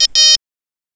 Alarm von reichweitetest während ein Flug.
Es war kein Piepton, es war wie ich geschrieben habe das Signal was auch bei der reichweitetest kommt.